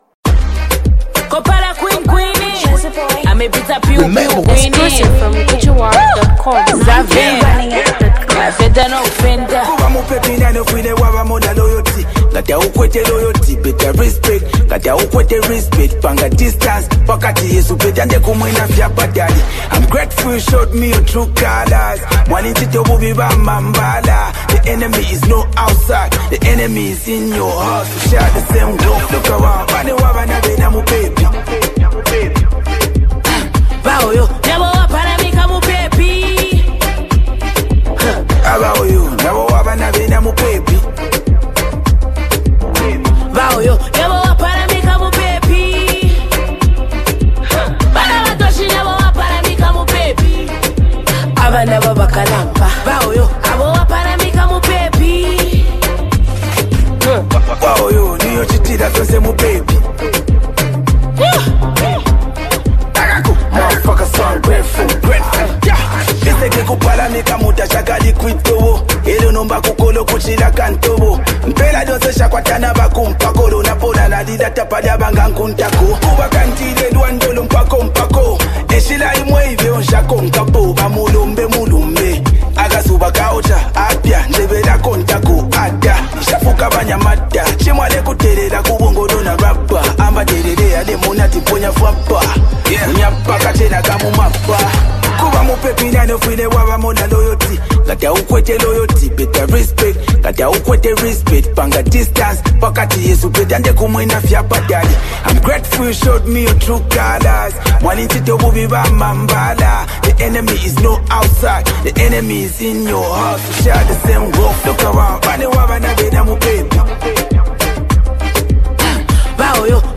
a rap sensation from Zambia’s Copperbelt Province